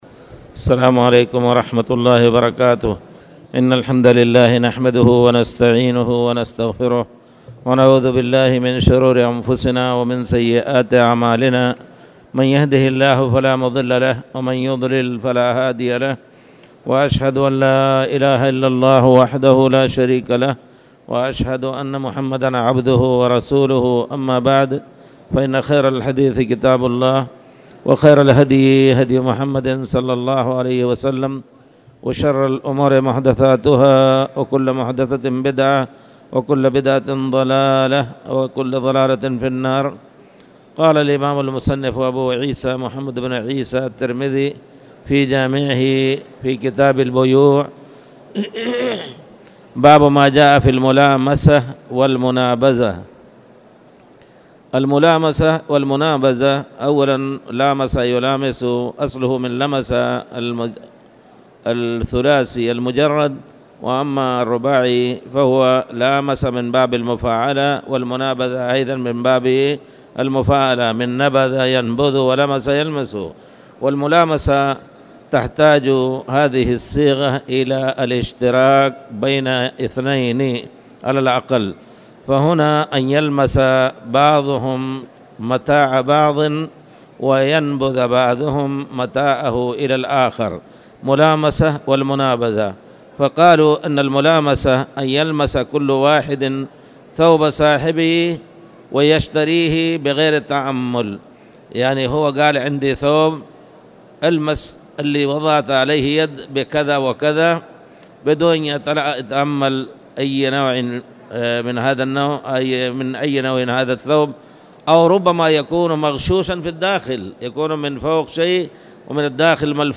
تاريخ النشر ٢٠ ربيع الثاني ١٤٣٨ هـ المكان: المسجد الحرام الشيخ